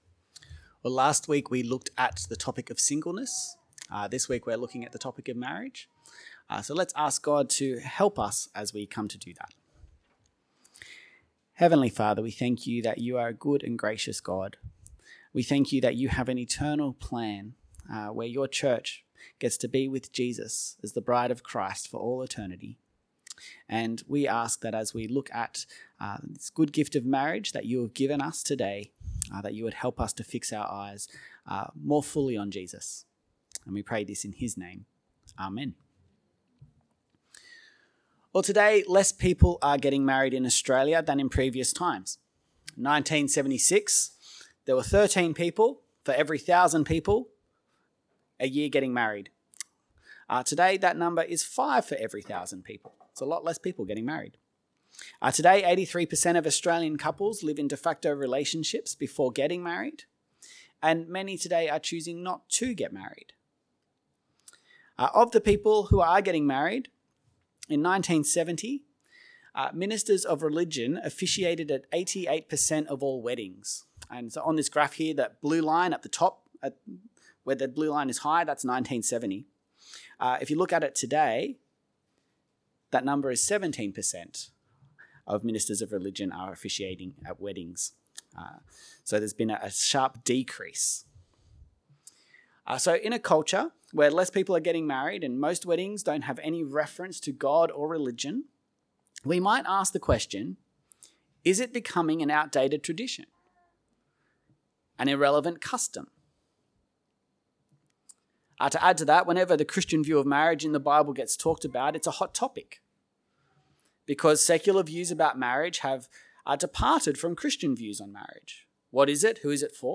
Sermon Series - St. James Anglican Church Kununurra